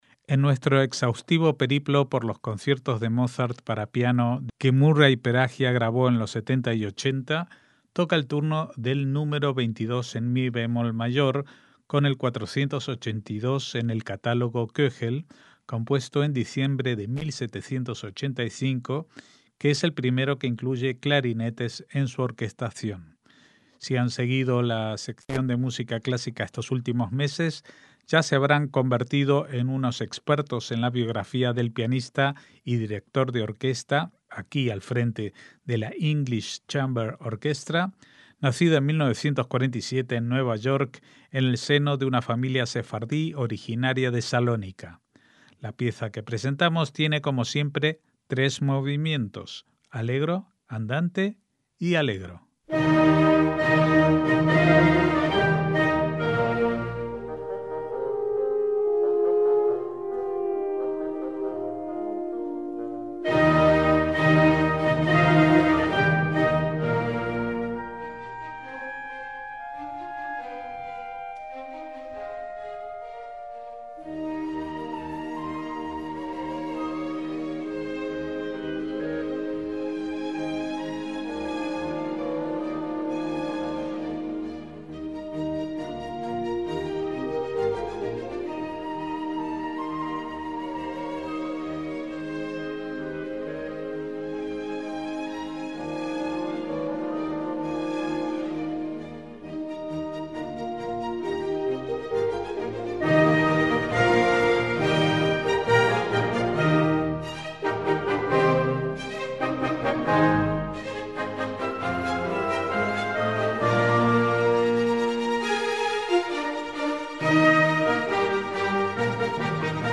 MÚSICA CLÁSICA
en mi bemol mayor
que es el primero que incluye clarinetes en su orquestación